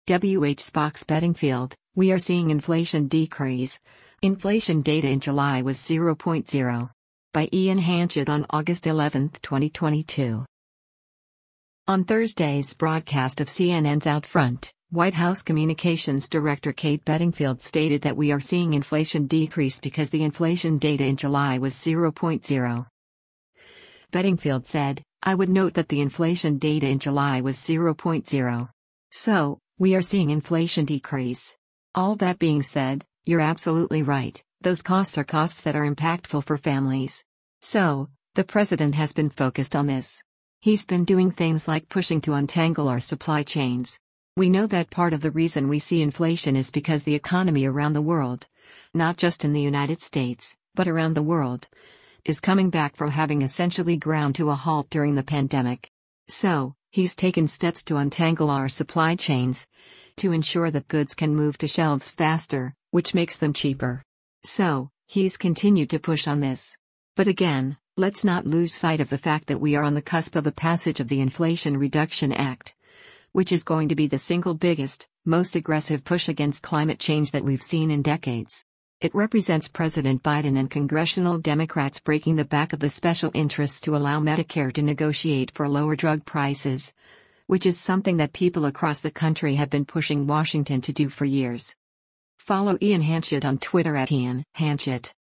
On Thursday’s broadcast of CNN’s “OutFront,” White House Communications Director Kate Bedingfield stated that “we are seeing inflation decrease” because “the inflation data in July was 0.0.”